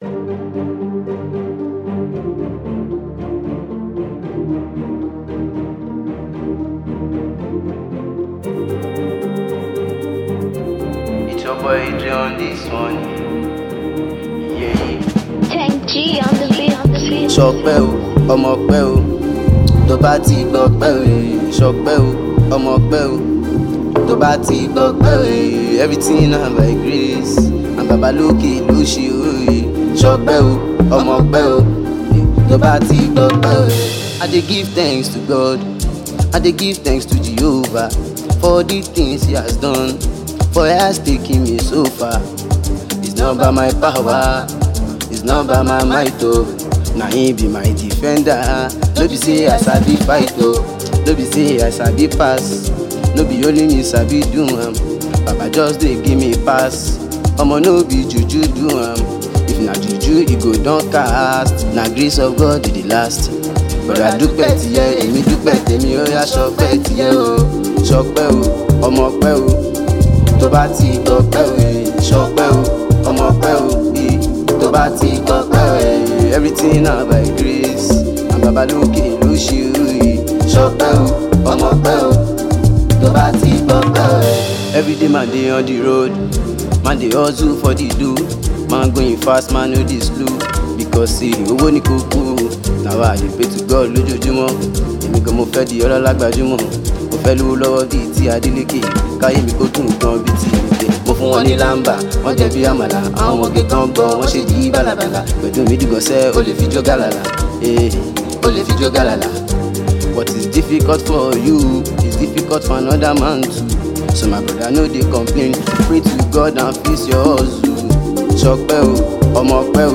amapiano vibe